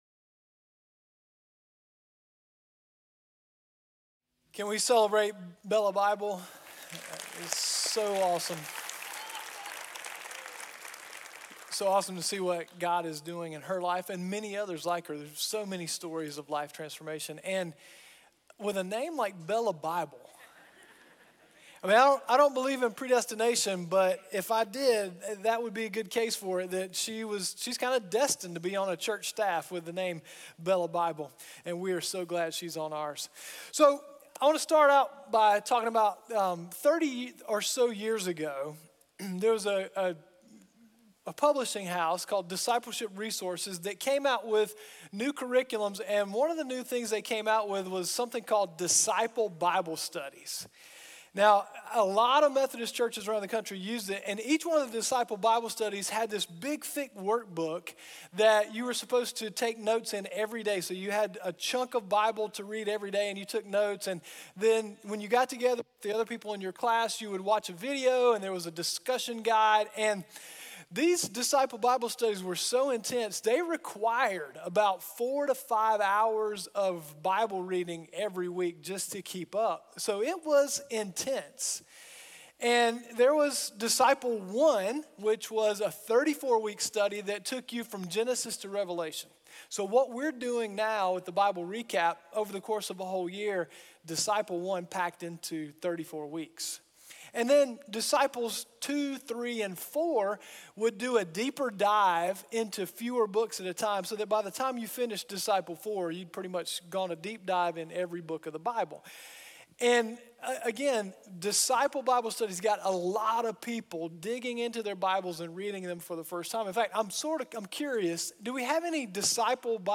Contemporary Service